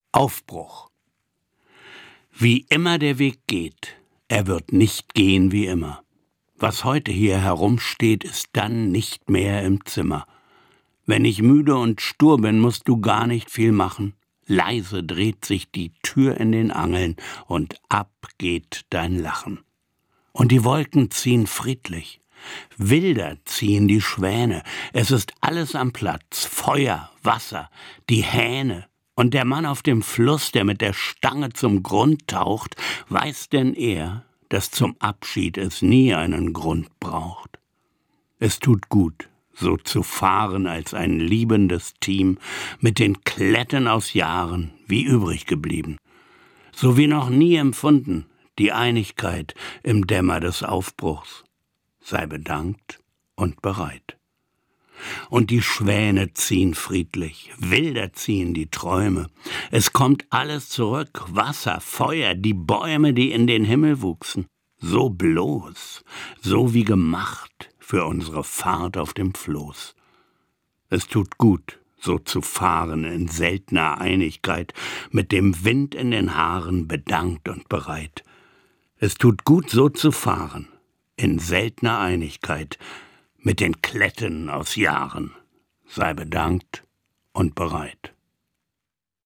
Das radio3-Gedicht der Woche: Dichter von heute lesen radiophone Lyrik.
Gelesen von Manfred Maurenbrecher.